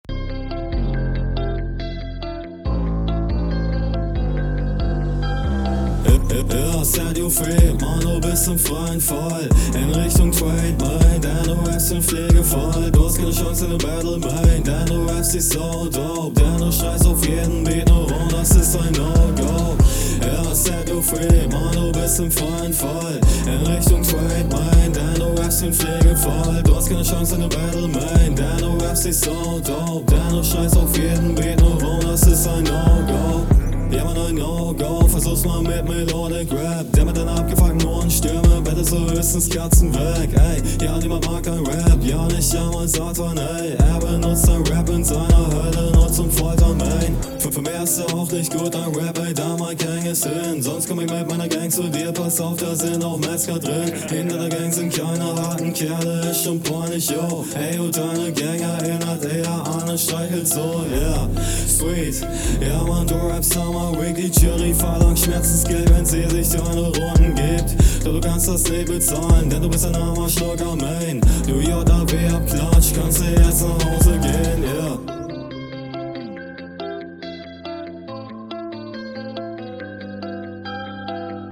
Deine Stimme wieder recht abwechslungslos, aber dafür etwas melodischer in der Hook und flowlich mal …
die probleme deiner verständlichkeit ziehen sich weiter durch deine hr3. stimmeinsatz fühl ich nicht. lines …